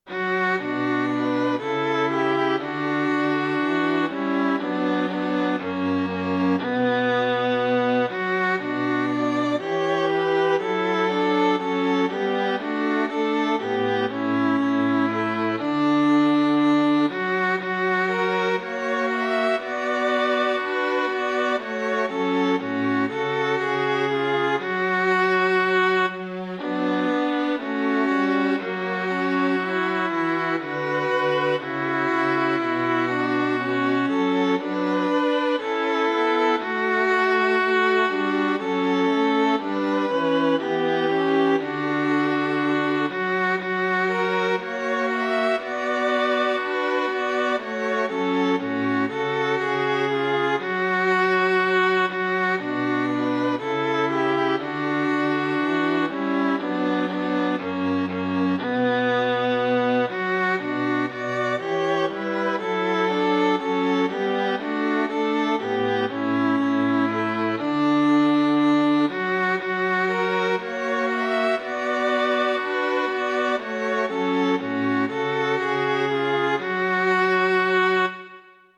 SAB korsats bygget over egen melodi 2008